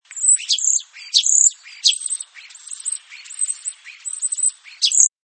Śpiew szpaków jest bardzo różnorodny, ponieważ potrafią one naśladować głosy innych gatunków ptaków, jak również inne zasłyszane dźwięki.